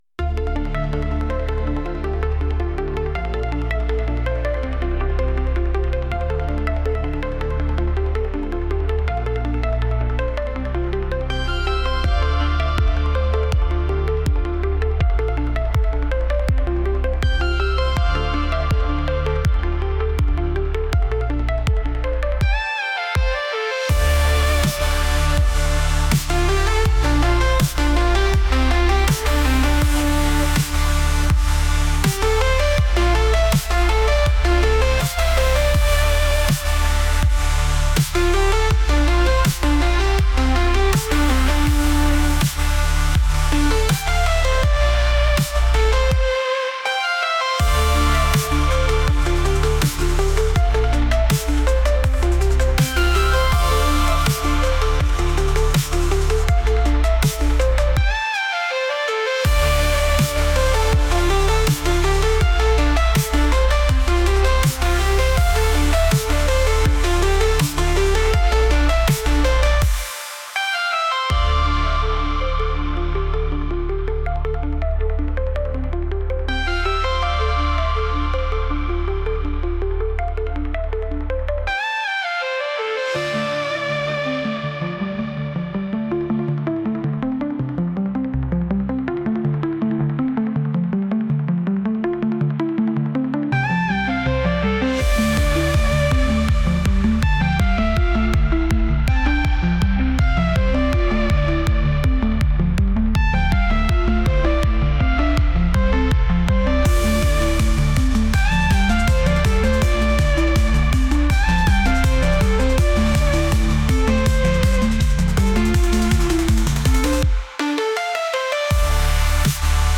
Futuristic Track